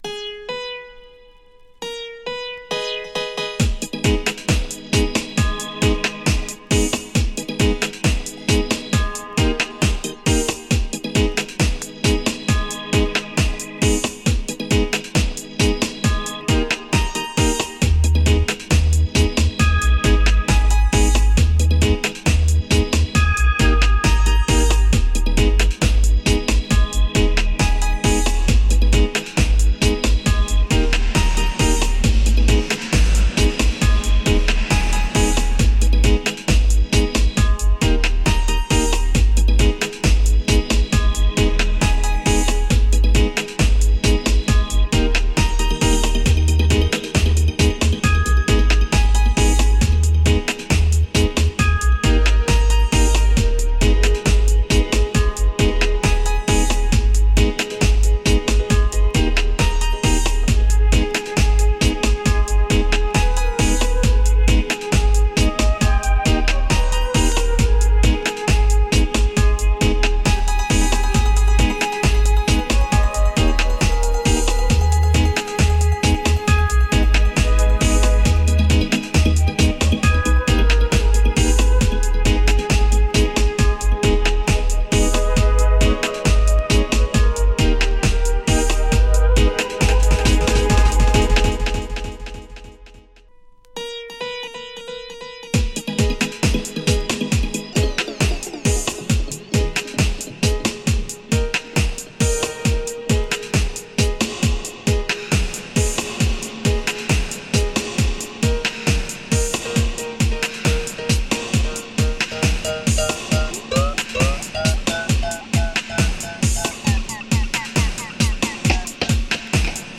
Side B : Dubwise